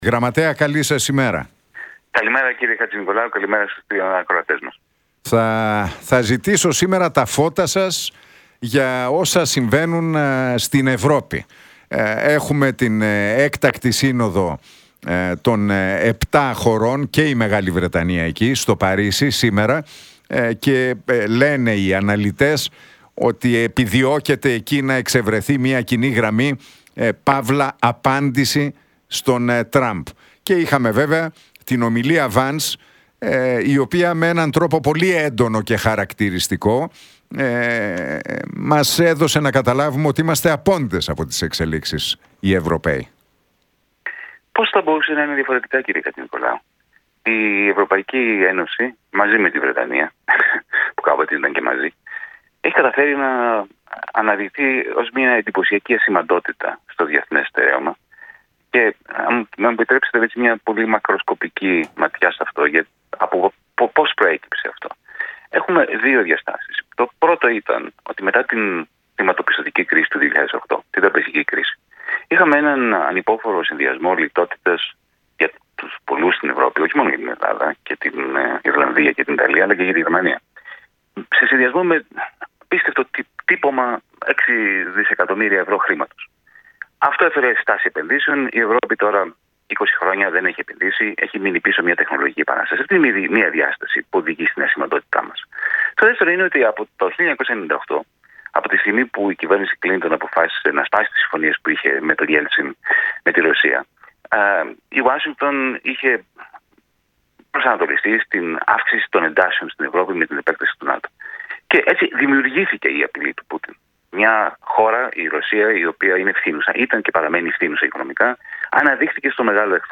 Για τα όσα συμβαίνουν στην Ευρώπη μετά την παρέμβαση Τραμπ και με φόντο την έκτακτη Σύνοδο στο Παρίσι αλλά και για τα ελληνοτουρκικά μίλησε μεταξύ άλλων ο Γιάνης Βαρουφάκης στον Realfm 97,8 και την εκπομπή του Νίκου Χατζηνικολάου.